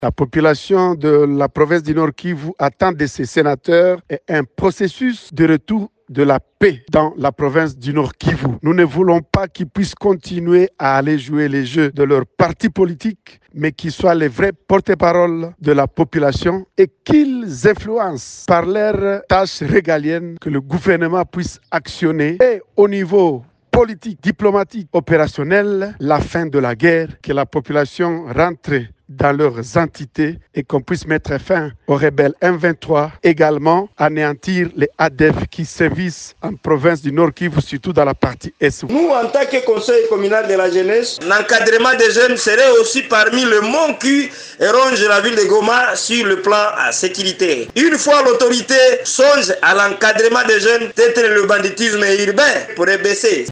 Les responsables de ces structures ont exprimé cette attente lundi 27 mai à Goma au lendemain de l’élection sénatoriale dans cette province.